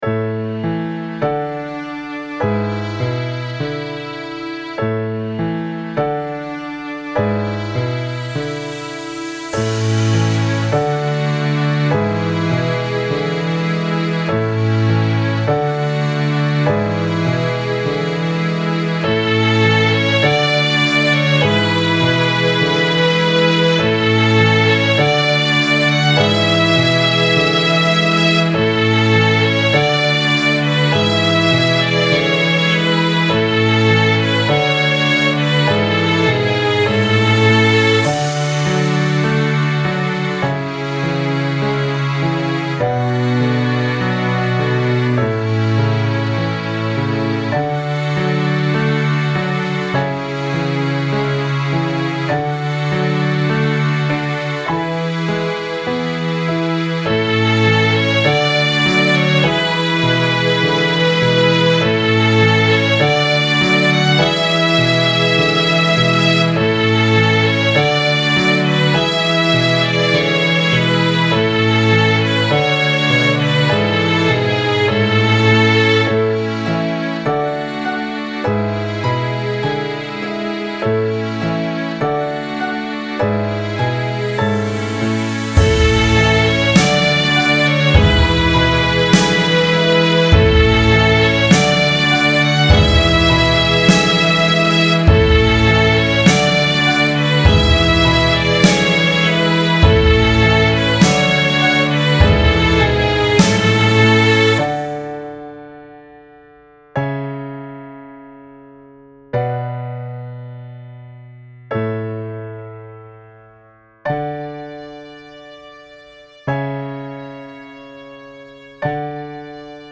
結婚式のエンディングムービーのBGMをイメージして作りました。BPMは101なので、一応割り切れない数字です。